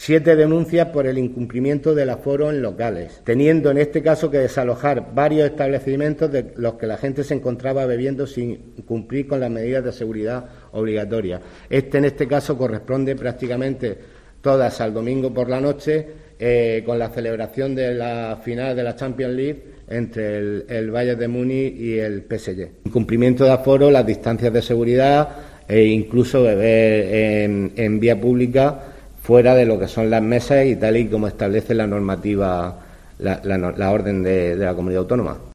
José Luis Ruiz Guillén, edil de seguridad del Ayuntamiento de Lorca